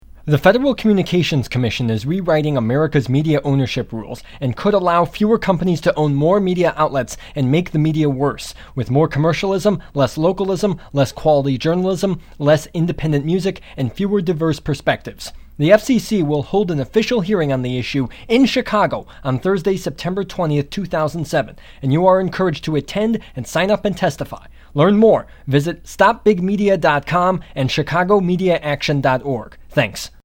Download 30-second radio announcement (MP3) | Updated 30-second radio announcement (MP3) | Coverage from Media Minutes